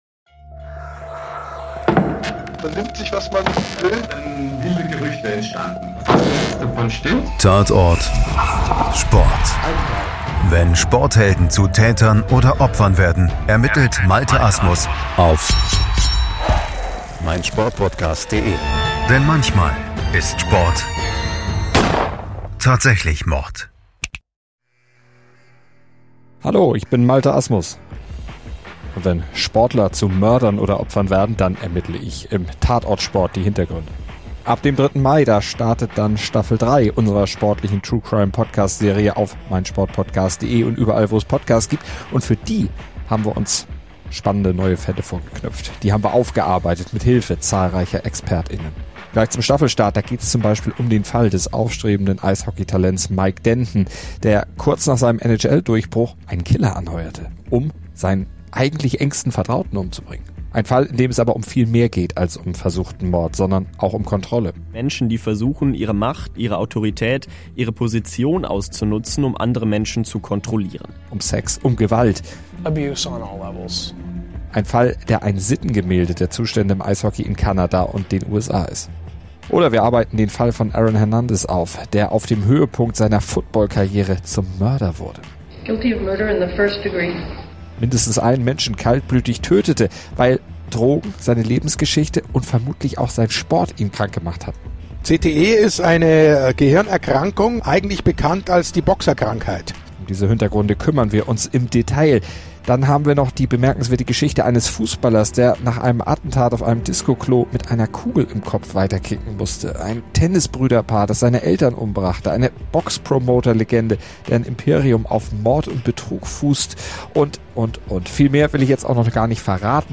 Das als Intromusik verwendete Soundfile trägt den Titel "Cinematic Crime Suspense", wurde erstellt von tyops.
Das als Hintergrundmusik verwendete Soundfile trägt den Titel "Your Number's Up", wurde erstellt von Scott Buckley.